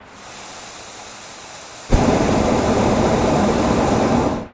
Есть и такой вариант с надувом воздушного шара